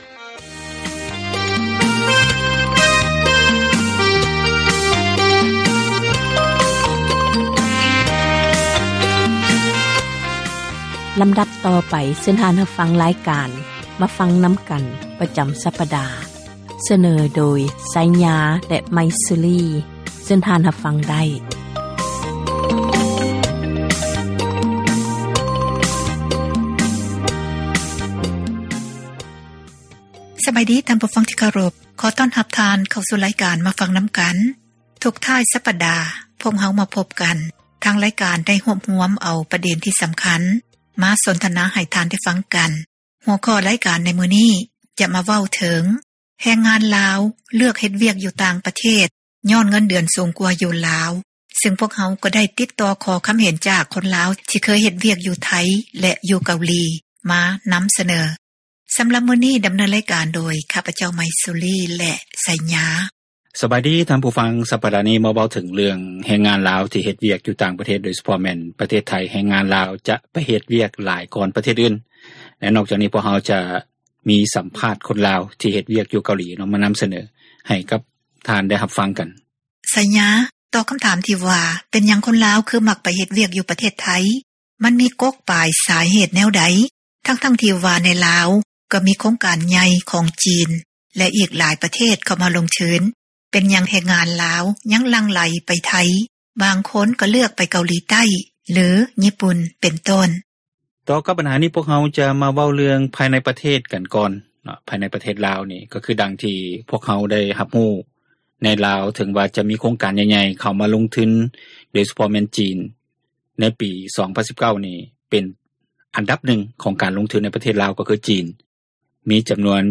ການສົນທະນາ ໃນຫົວຂໍ້ ບັນຫາ ແລະ ຜົລກະທົບ ຢູ່ ປະເທດລາວ